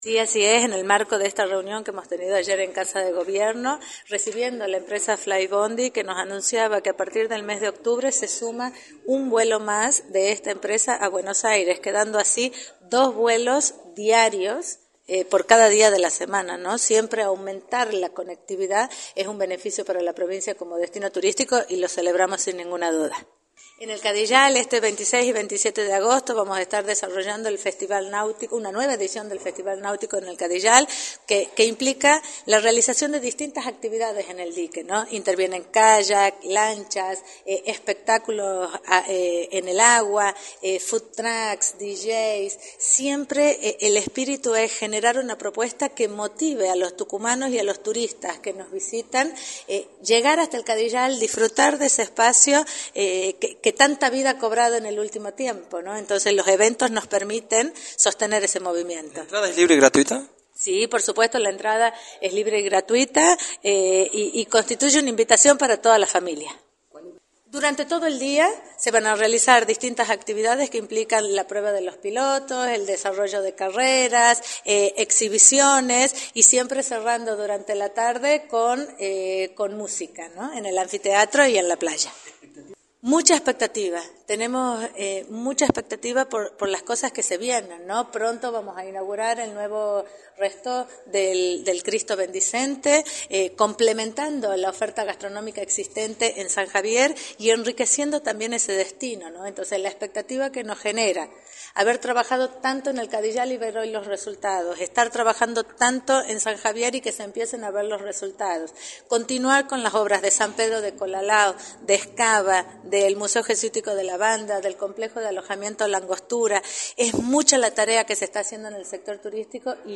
Elena Colombres Garmendia, Vicepresidente del Ente Tucumán Turismo, informó en Radio del Plata Tucumán, por la 93.9, que la empresa Fly Bondi sumará un vuelo a Buenos Aires y remarcó cuáles son las expectativas para los próximos meses.